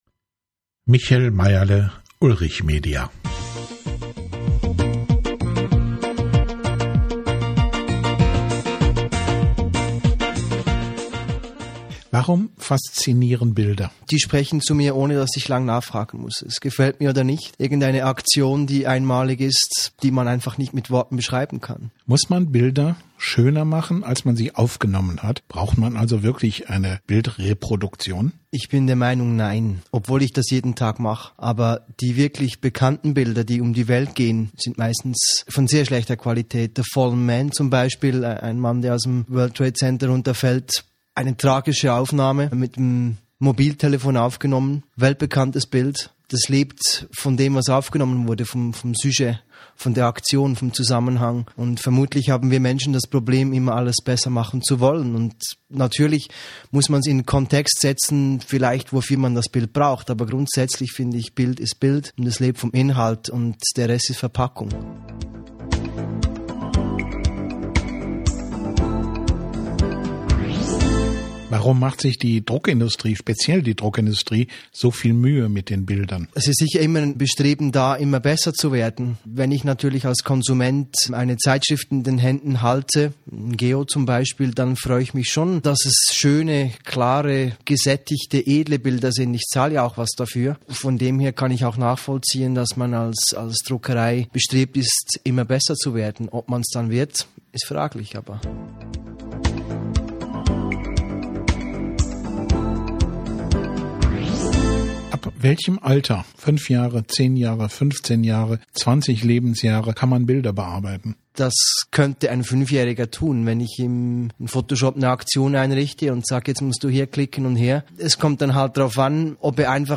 Interview, ca. 5 Minuten